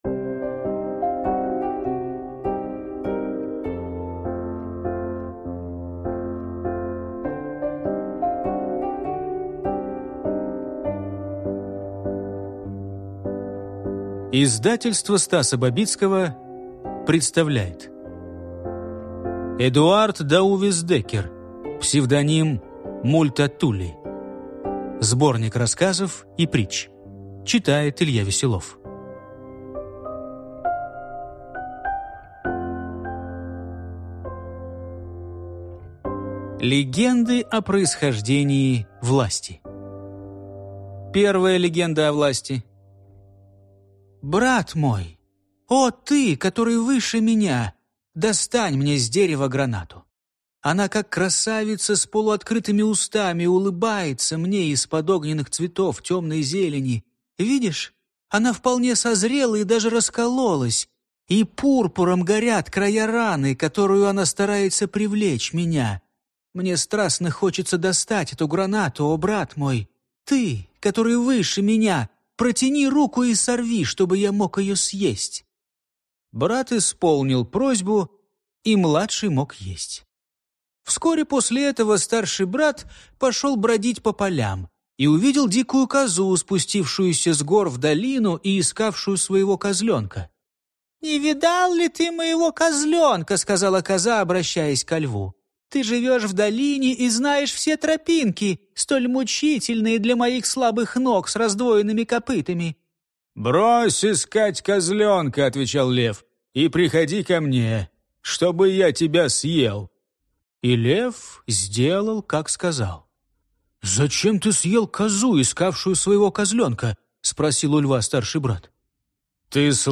Аудиокнига Легенды о происхождении власти | Библиотека аудиокниг
Прослушать и бесплатно скачать фрагмент аудиокниги